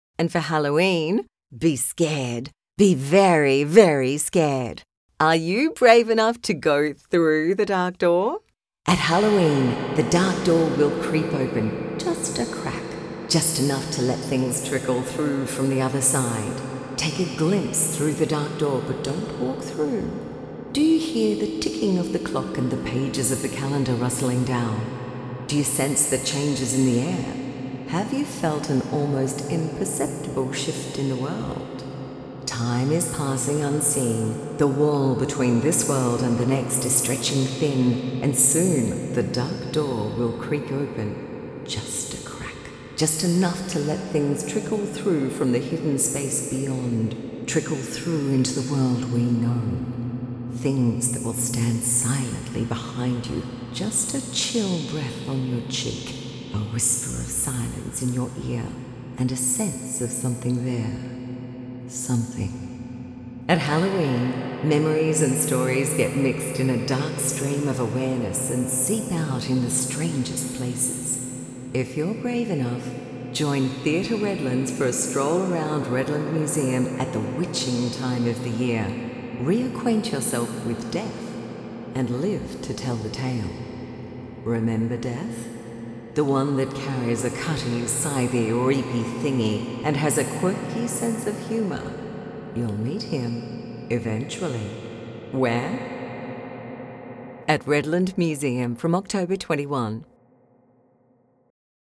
redland_museum_full_spooky.mp3